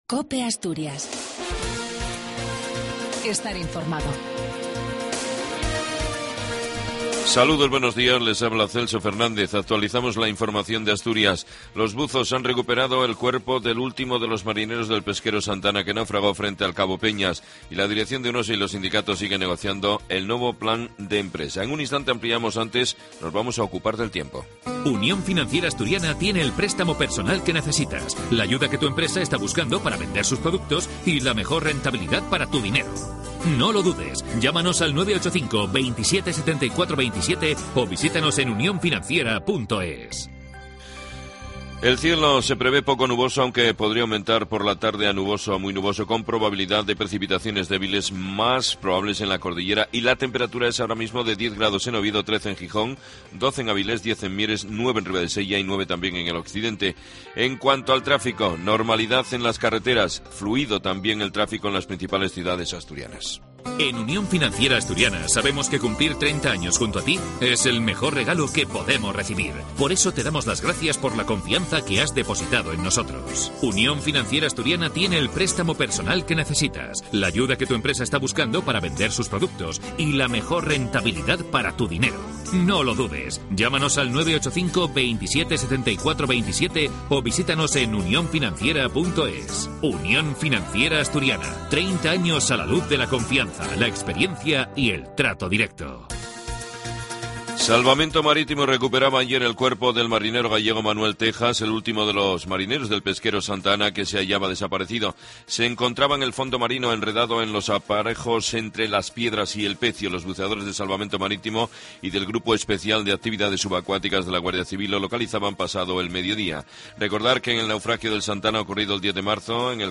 AUDIO: LAS NOTICIAS ASTURIAS A PRIMERA HORA DE LA MAÑANA.